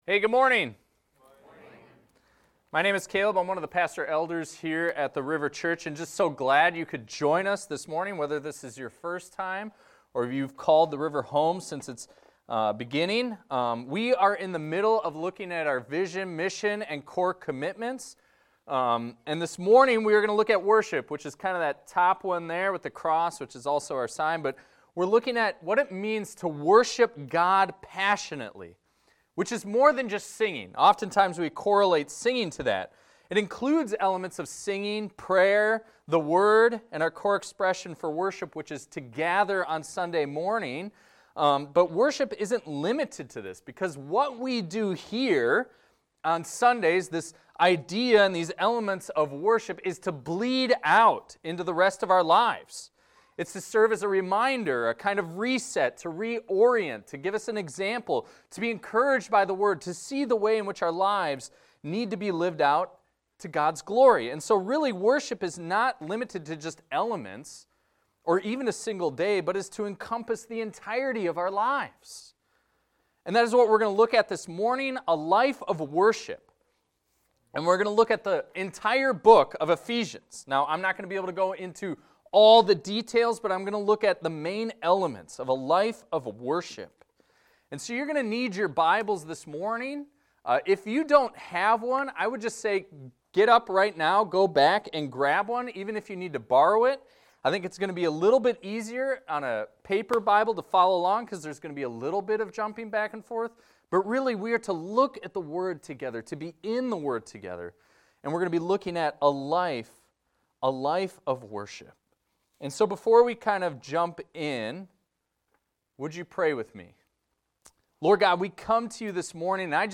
This is a recording of a sermon titled, "A Life of Worship."